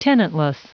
Prononciation du mot tenantless en anglais (fichier audio)
Prononciation du mot : tenantless